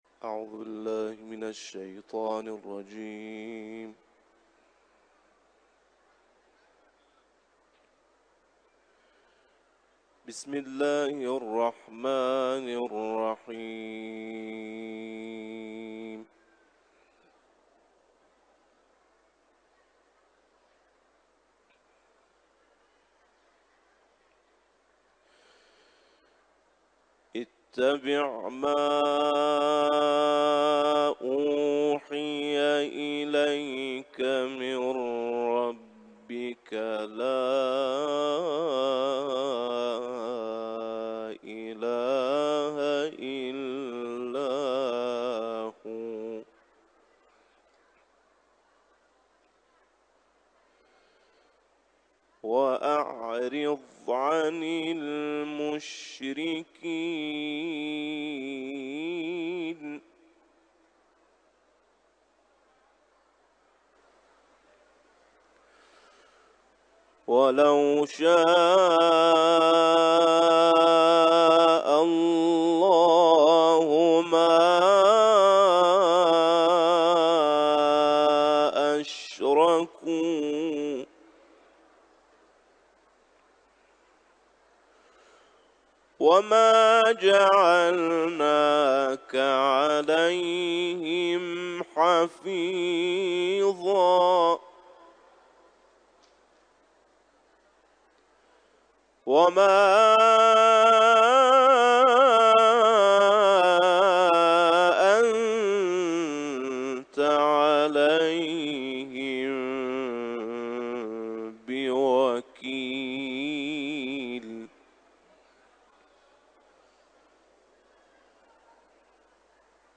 حرم مطهر رضوی